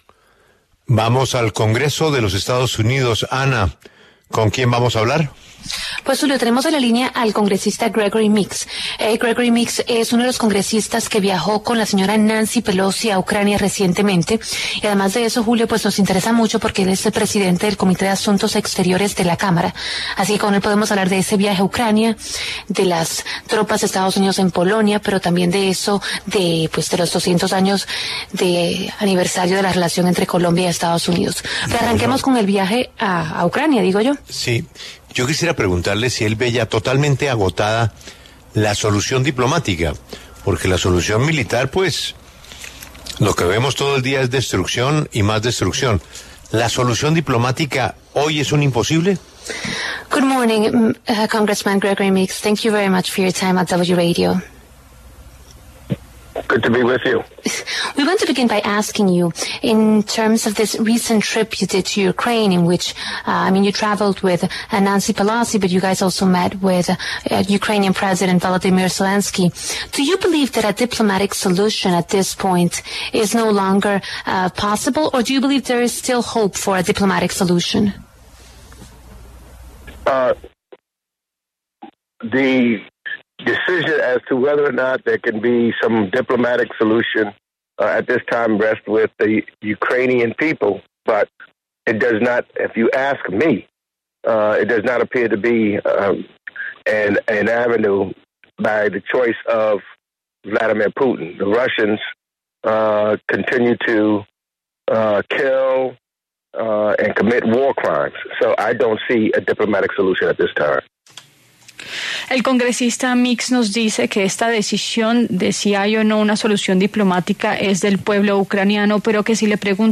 Gregory Meeks, congresista demócrata y presidente del Comité de Asuntos Exteriores de la Cámara en EE.UU., habló en La W sobre su reciente viaje a Ucrania con Nancy Pelosi.